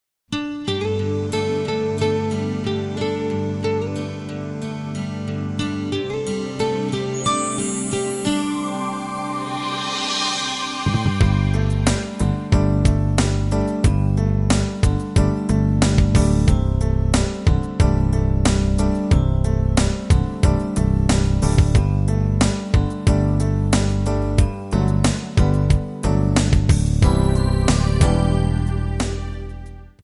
Gb
Backing track Karaoke
Pop, 1990s